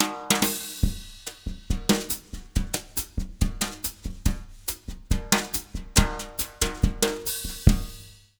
140BOSSA06-L.wav